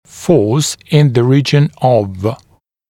[fɔːs ɪn ðə ‘riːʤ(ə)n əv ….. græmz][фо:с ин зэ ‘ри:дж(э)н ов ….. грэмз] сила около (в районе) …. гр.